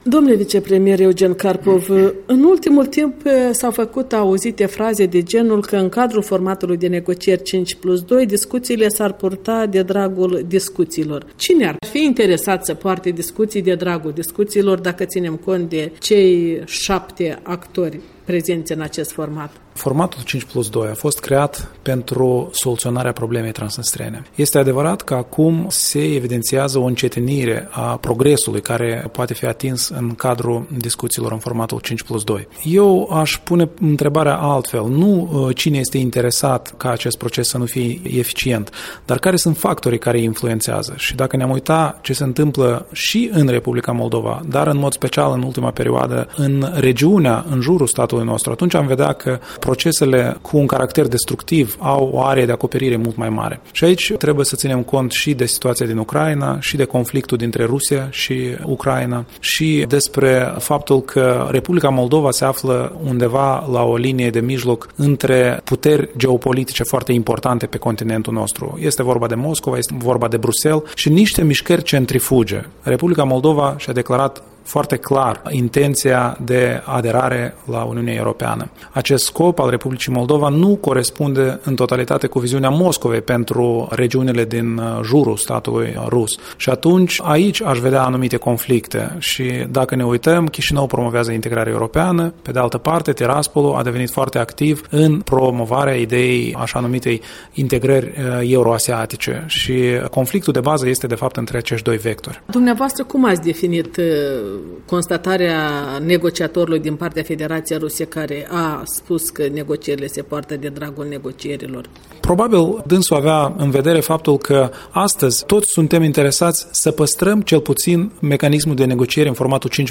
Interviu cu vicepremierul pentru reintegrare Eugen Carpov